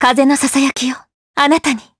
Laudia-Vox_Skill4_jp.wav